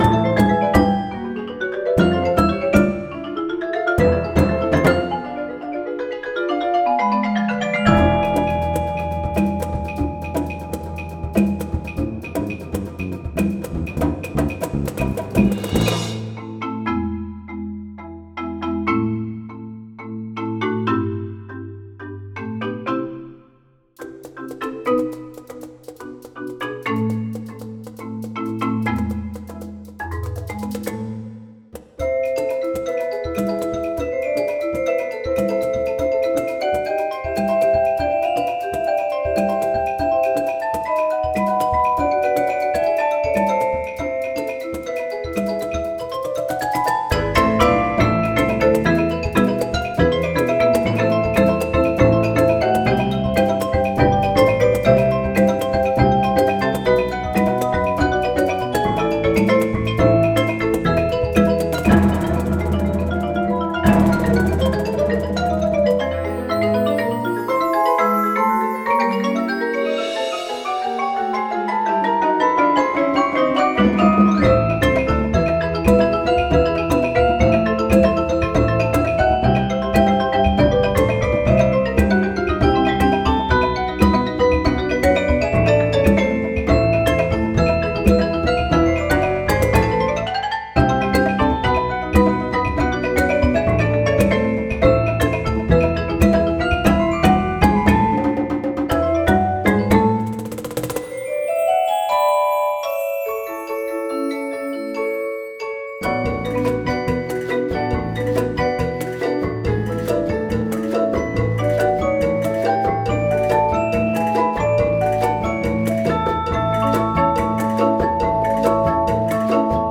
Voicing: 14 Percussion